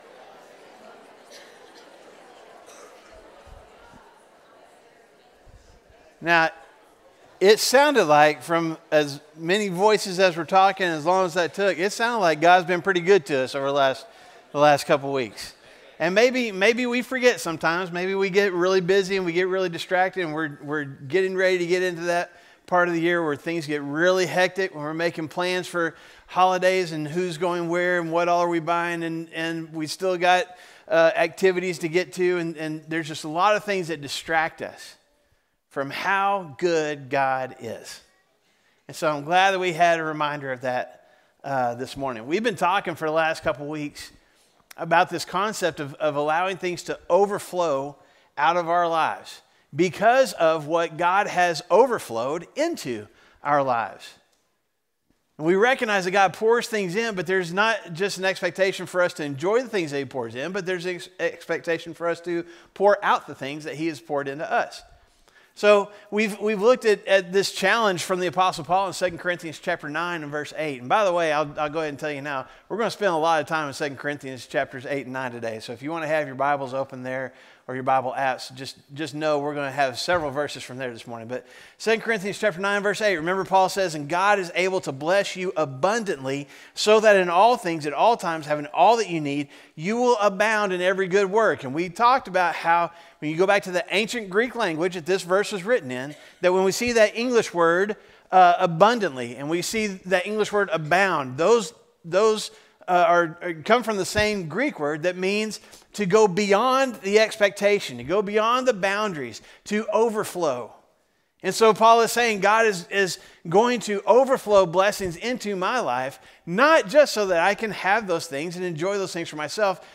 A message from the series "Overflow." Regardless of the holiday season, HOPE can sometimes be in short supply.